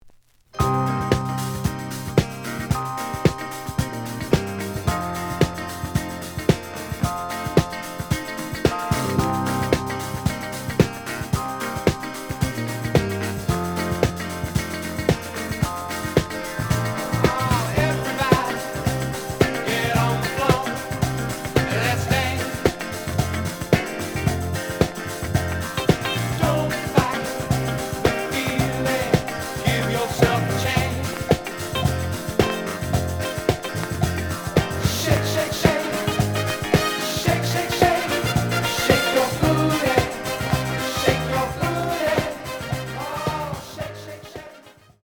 The audio sample is recorded from the actual item.
●Genre: Disco
Some click noise on first half of A side.